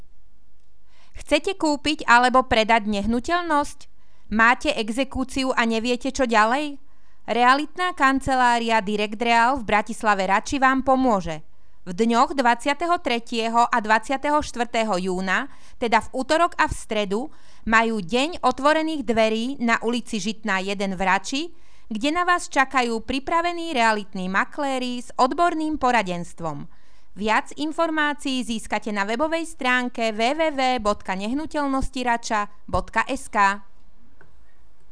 Hlásenie miestneho rozhlasu 22., 23.6.2015
komercne_hlasenie_-_directreal.wav